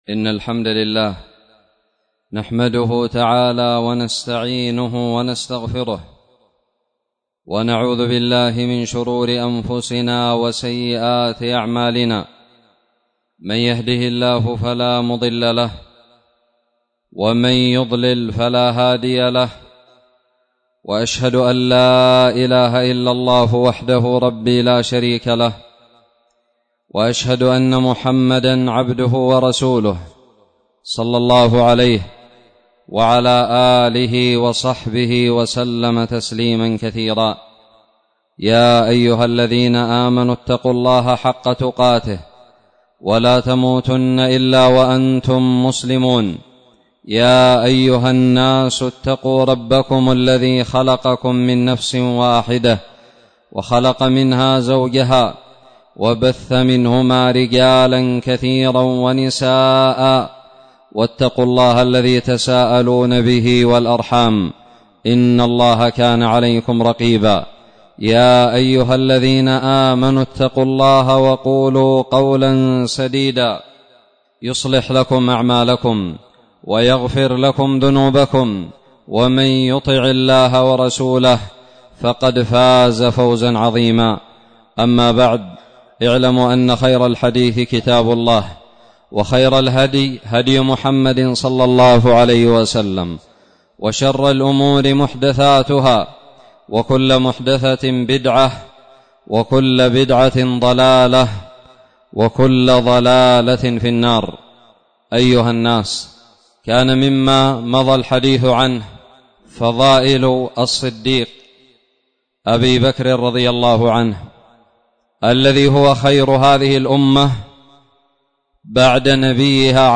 خطب الجمعة
ألقيت بدار الحديث السلفية للعلوم الشرعية بالضالع في 19 ربيع الآخرة 1442هــ